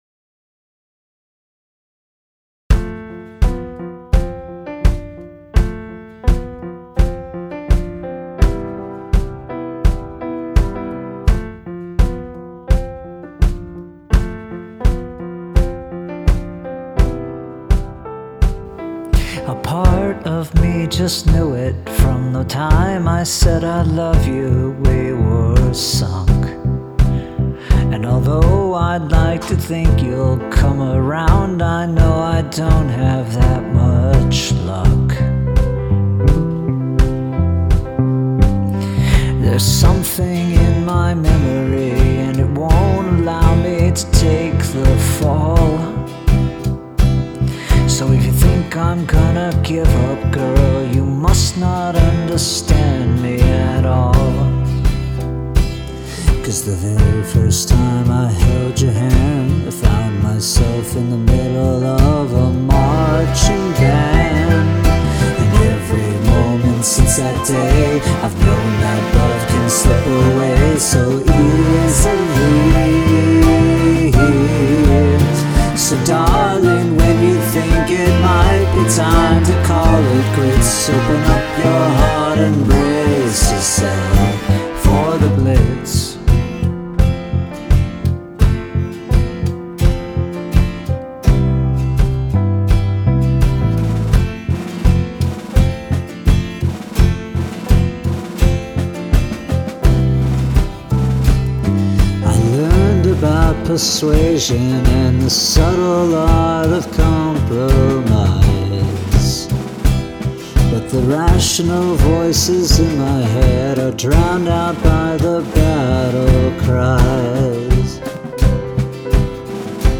Use of choral voices
smooth. I like how the song builds.
Wild bass line for such a breezy song.